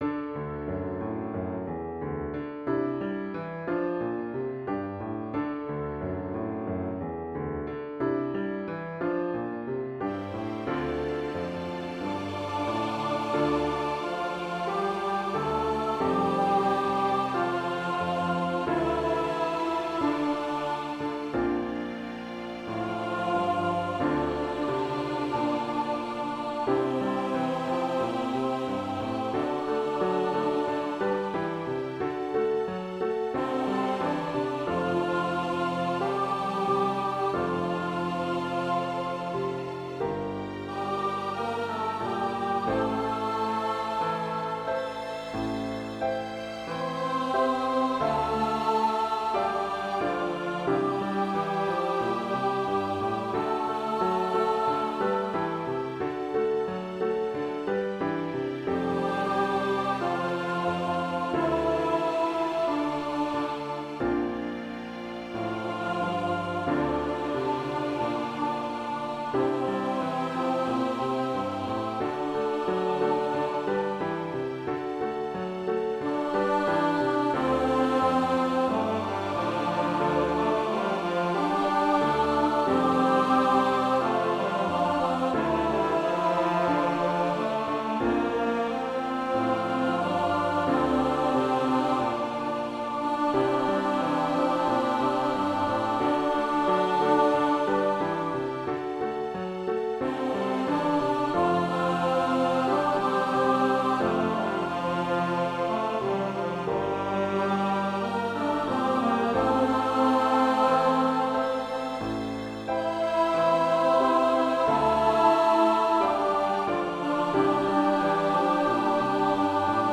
Voicing/Instrumentation: Trio
Piano